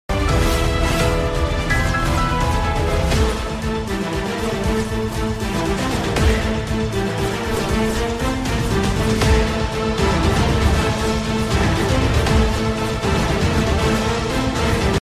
Boss battle synchronized with the music!